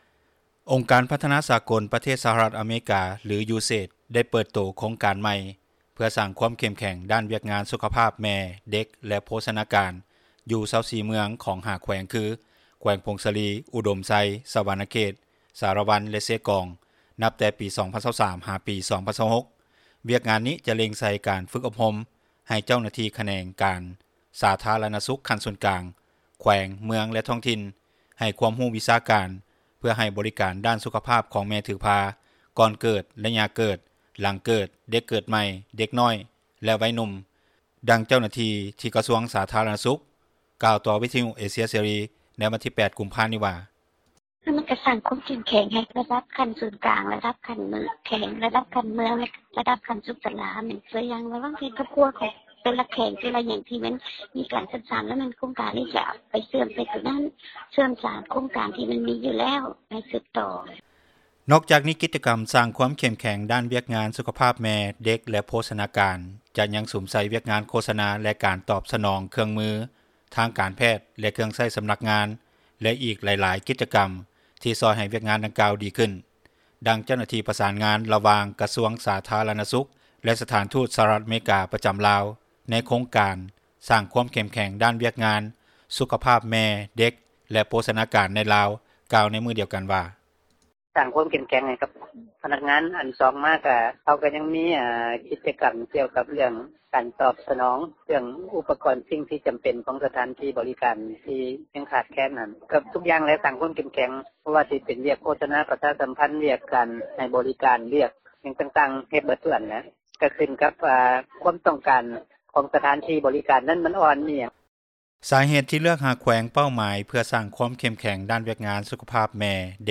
ດັ່ງເຈົ້າໜ້າທີ່ ກະຊວງສາທາຣະນະສຸຂ ກ່າວຕໍ່ວິທຍຸ ເອເຊັຽເສຣີ ໃນວັນທີ 08 ກຸມພານີ້ວ່າ:
ດັ່ງເຈົ້າໜ້າທີ່ ທີ່ກ່ຽວຂ້ອງແຂວງສາຣະວັນ ກ່າວໃນມື້ດຽວກັນນີ້ວ່າ: